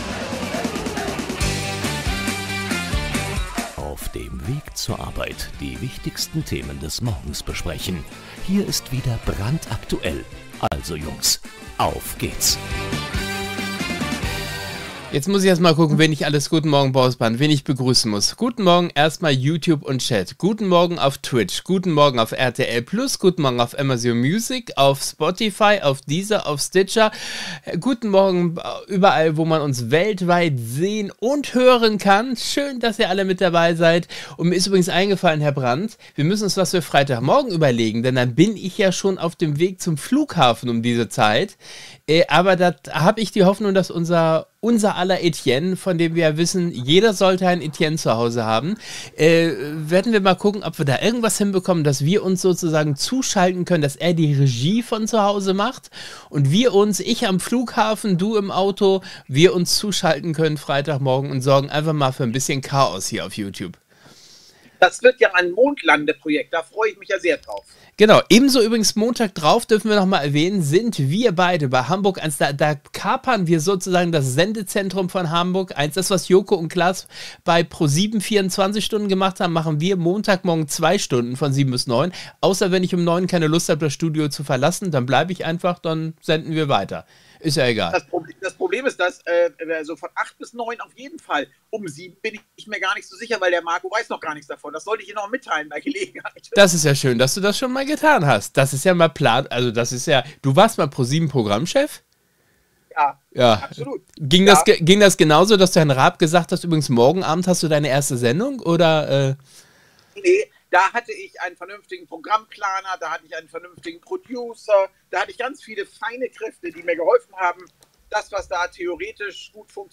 Die Themen des Morgens! Wie immer lustig, sarkastisch und manchmal auch politisch nicht korrekt.